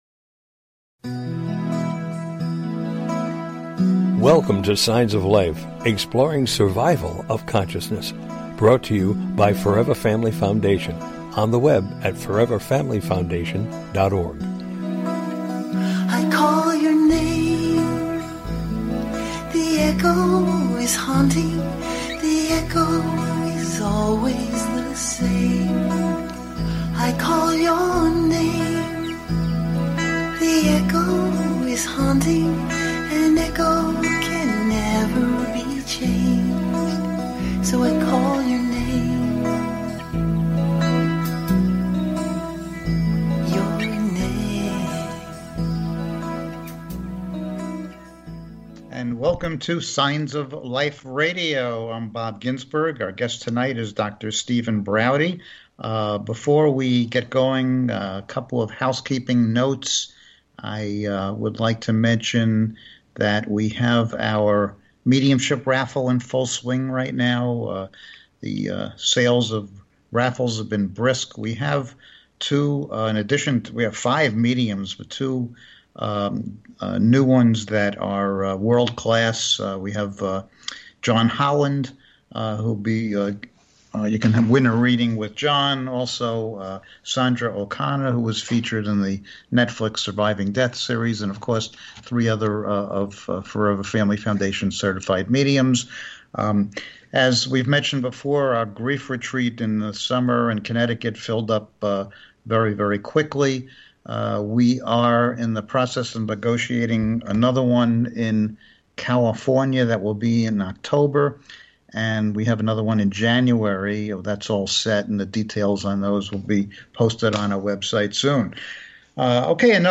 The Gathering: A Discussion about After Life Communication